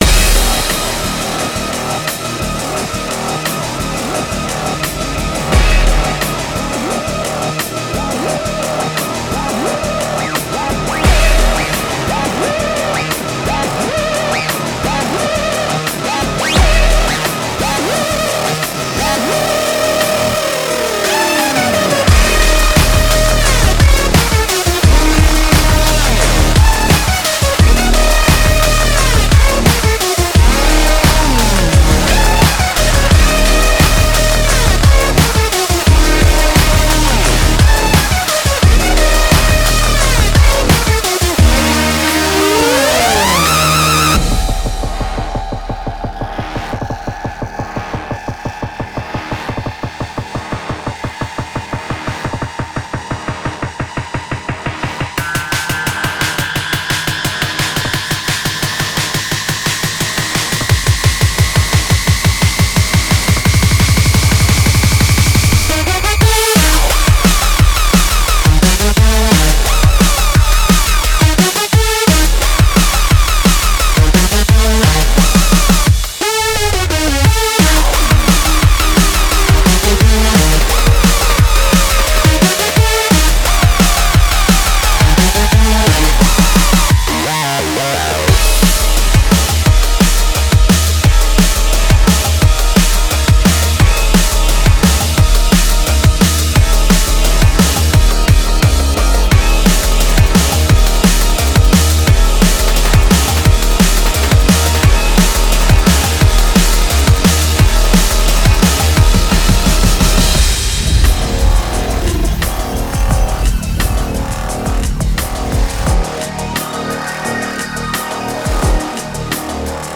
BPM87-174
MP3 QualityMusic Cut